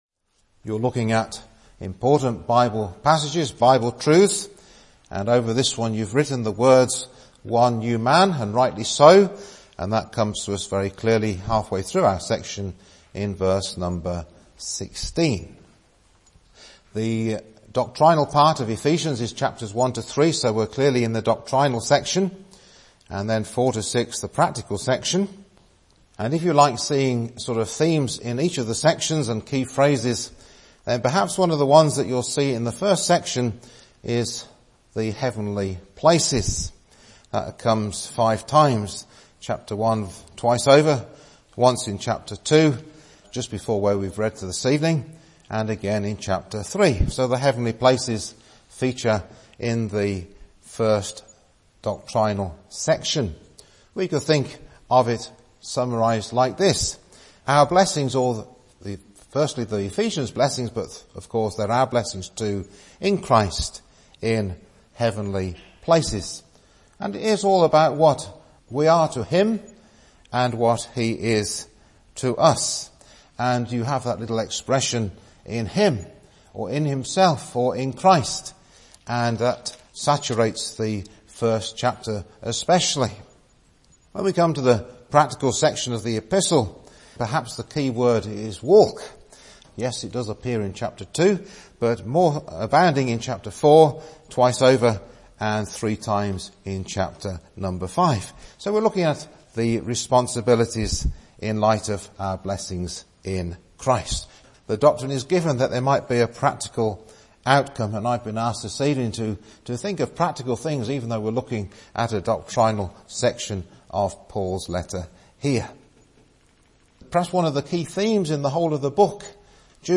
It was made possible by the work of Christ in breaking down the middle wall of partition at the cross (Message preached 6th Dec 2018)